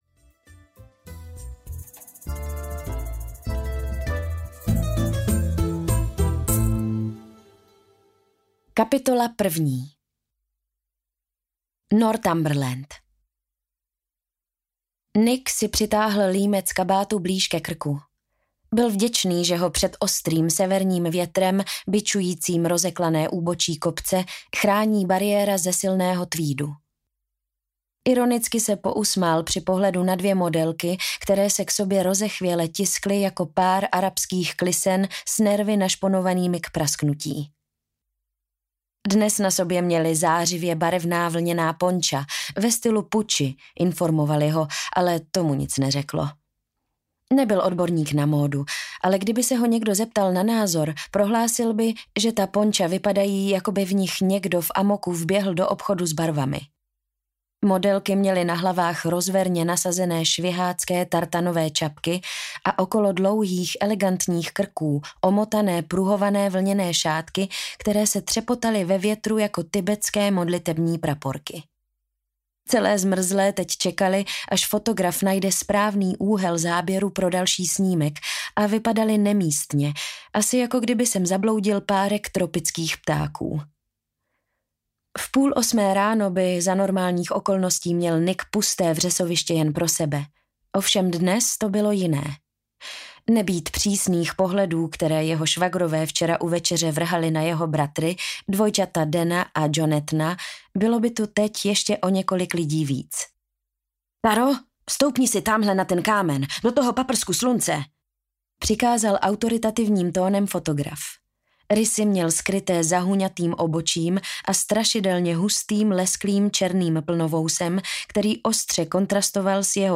Pláž v Chorvatsku audiokniha
Ukázka z knihy